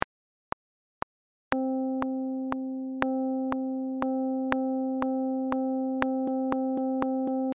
Note values in 3/4 time signature example
Note-values-in-34-audio-example.mp3